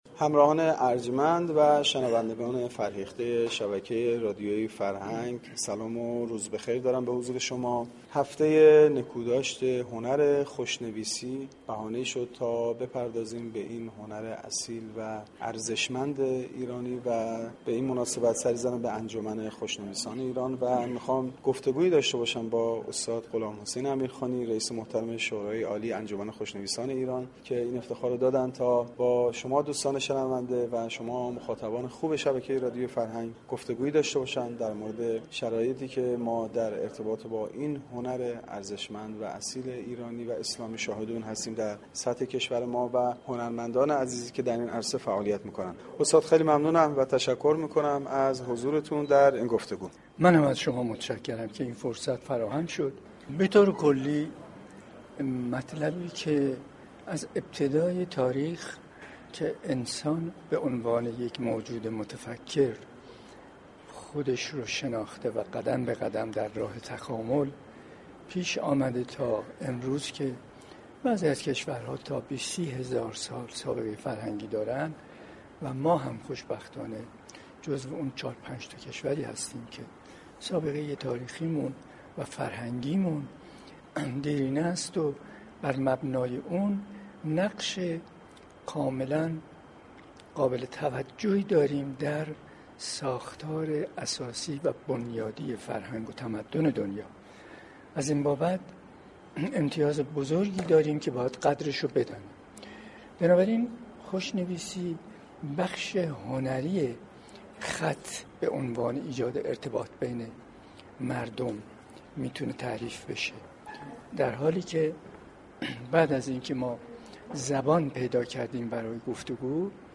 در گفتگو با گزارشگر رادیو فرهنگ